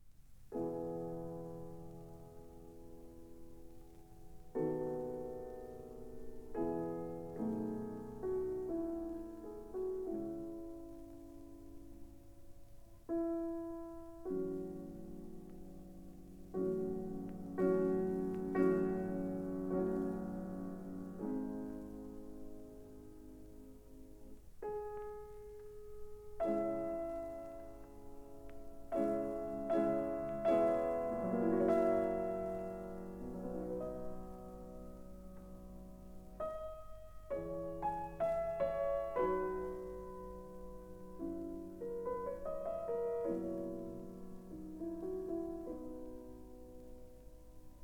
in C minor, Op. 37